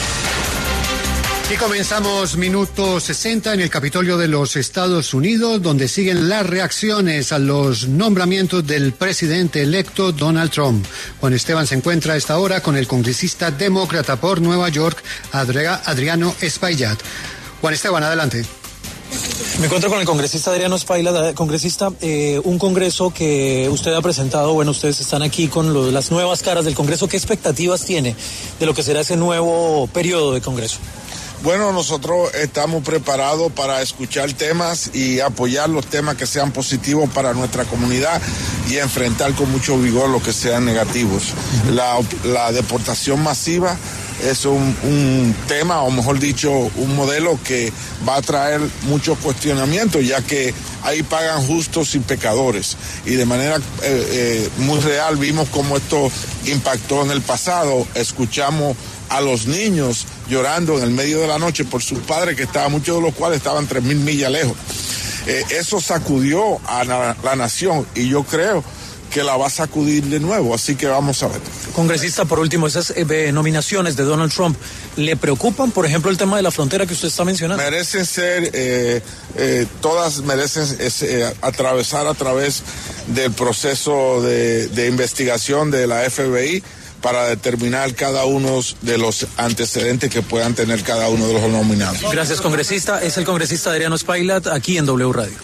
El congresista demócrata, Adriano Espaillat, conversó con La W sobre la futura gestión de Donald Trump, advirtiendo que la deportación masiva puede generar consecuencias al país.
El congresista demócrata, Adriano Espaillat, pasó por los micrófonos de La W para hablar sobre el tema, mencionando que estaban listos para apoyar o frenar las iniciativas que el republicano decida tomar.